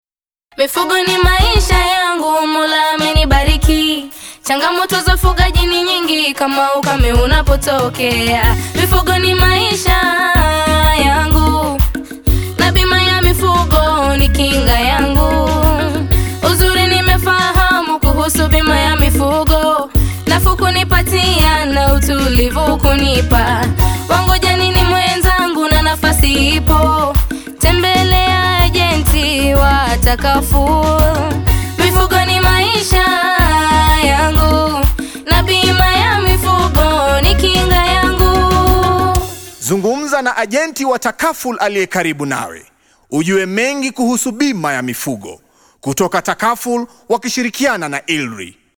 Audios Jingles Language English